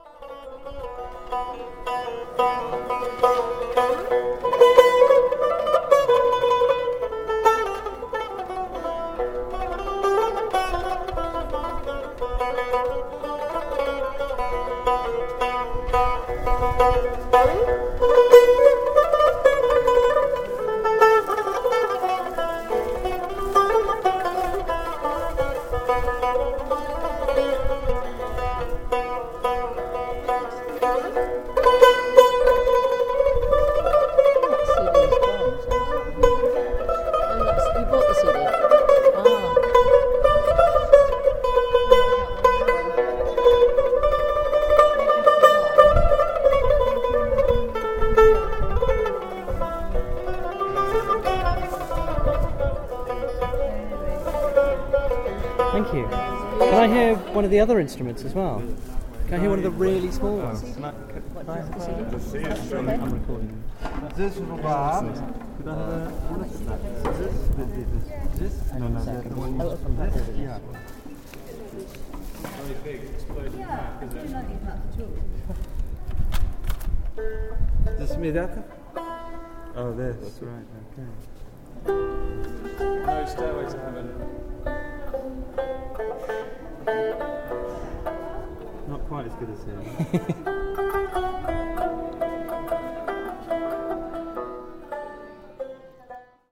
Traditional stringed instruments being played at a stall in a Bukhara bazaar, Uzbekistan.